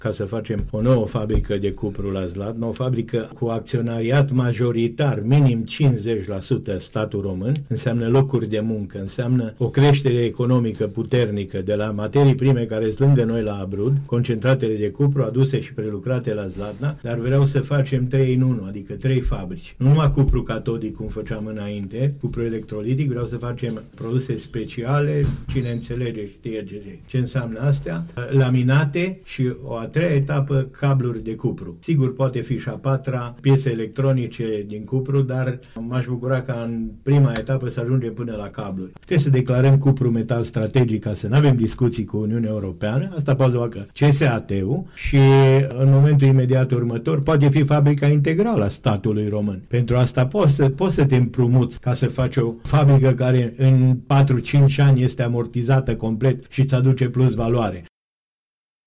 Silviu Ponoran a declarat la Unirea FM că își propune construcția a 3 fabrici, de la produse speciale, laminate până la cabluri din cupru.
De unde ar putea veni finanțarea și care ar fi avantajele pentru oraș, a precizat la Unirea FM primarul Zlatnei, Silviu Ponoran.